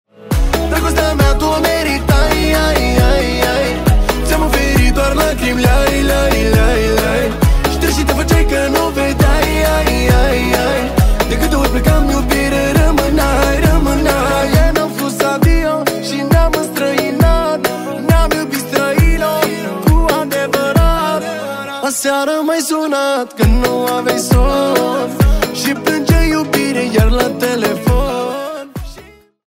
Manele